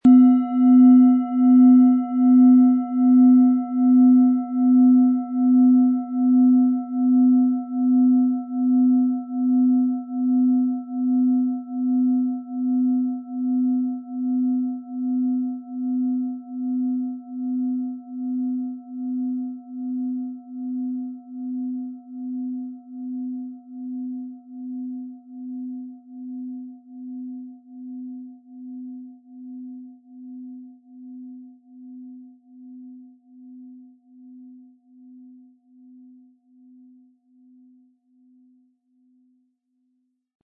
Von erfahrenen Meisterhänden in Handarbeit getriebene Klangschale.
• Tiefster Ton: Platonisches Jahr
Im Audio-Player - Jetzt reinhören hören Sie genau den Original-Ton der angebotenen Schale.
MaterialBronze